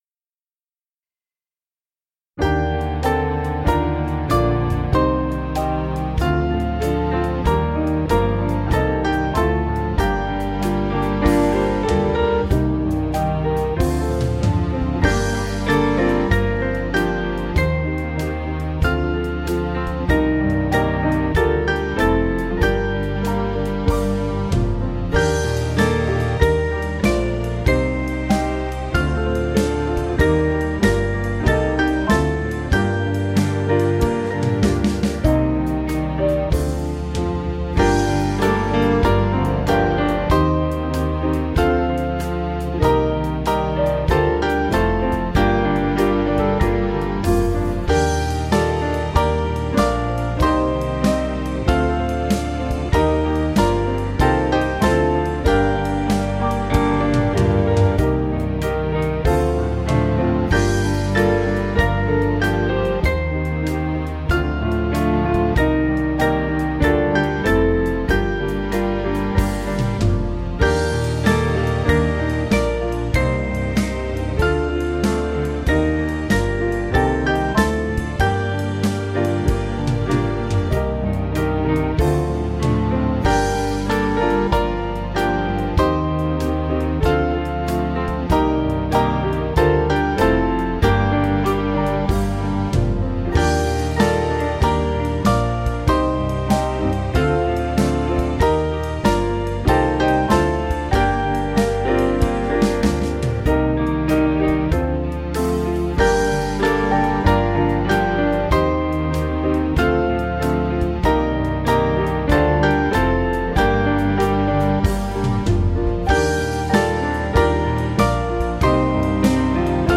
Small Band
(CM)   5/Gm 491.9kb